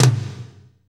Index of /90_sSampleCDs/Northstar - Drumscapes Roland/DRM_Slow Shuffle/KIT_S_S Kit 2 x
TOM S S M1AR.wav